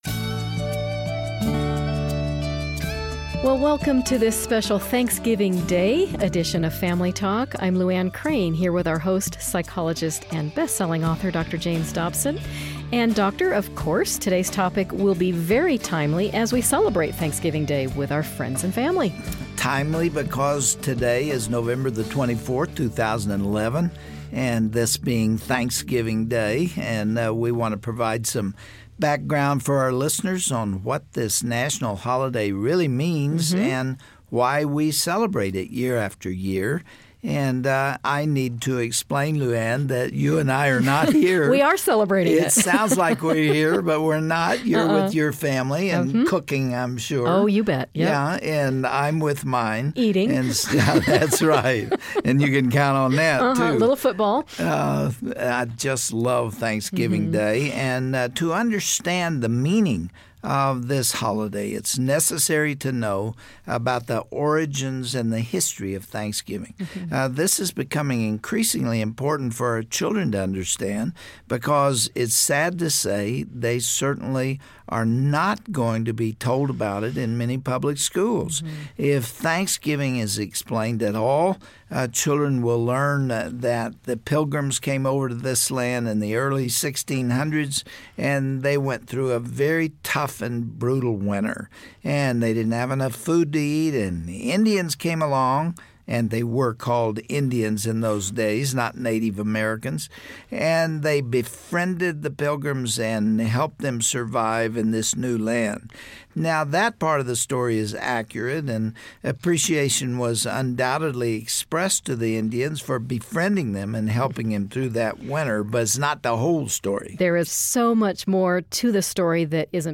Break out of the food coma and join in on a holiday conversation with Congressman Todd Akin, who will discuss the true meaning of Thanksgiving.